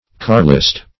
Search Result for " carlist" : The Collaborative International Dictionary of English v.0.48: Carlist \Car"list\ (k[aum]r"l[i^]st), n. A partisan of Charles X. of France, or of Don Carlos of Spain.